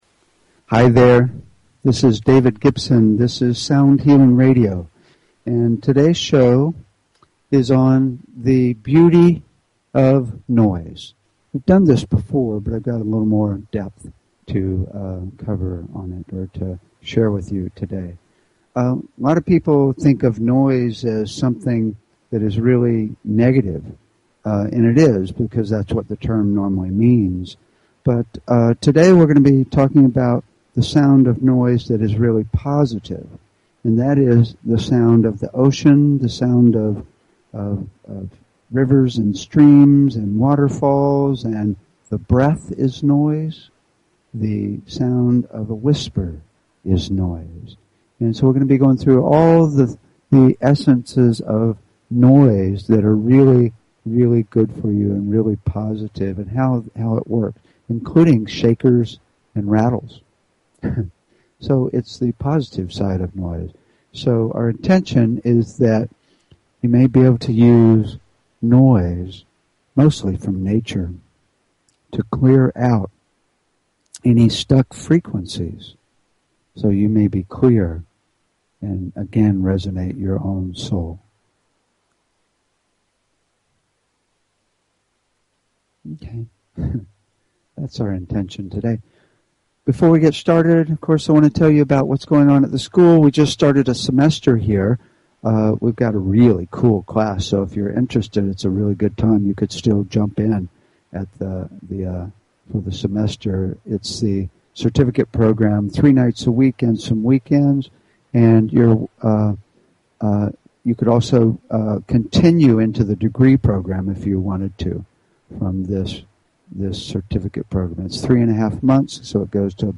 Talk Show Episode, Audio Podcast, Sound_Healing and Courtesy of BBS Radio on , show guests , about , categorized as
THE BEAUTY OF POSITIVE NOISE We'll be talking about and making the sounds of positive white and pink noises including: White noise, the ocean, rivers, streams, waterfalls, wind, shakers, rattles, whispers and the breath. We'll discuss how the chaotic sound of noise can be used to break up stuck frequencies such as emotions and blockages in the body.